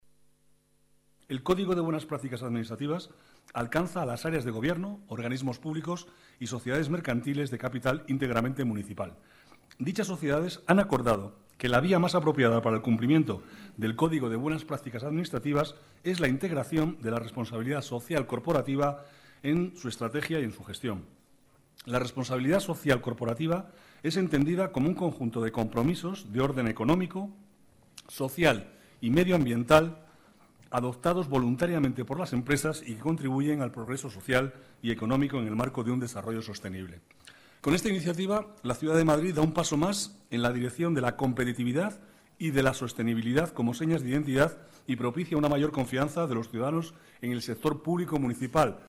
Nueva ventana:Declaraciones del vicealcalde, Manuel Cobo: Guía para una gestión más ética